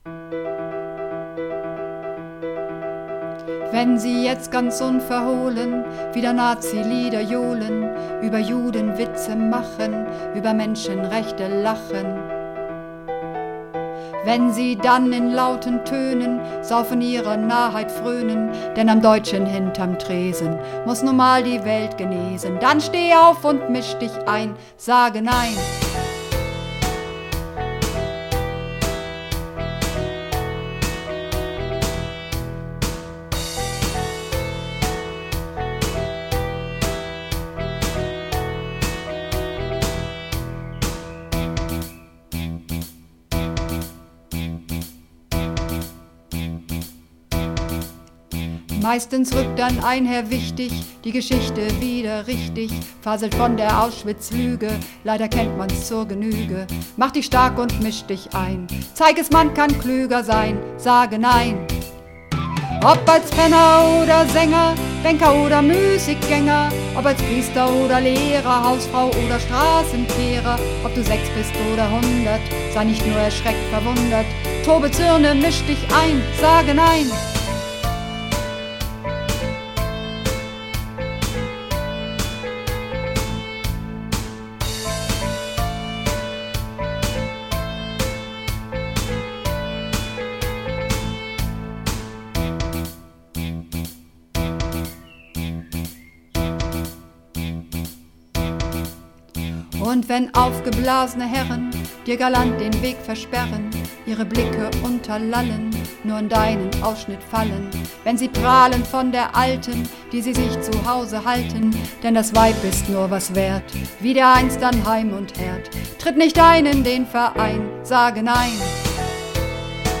Übungsaufnahmen
Sage Nein (Alt)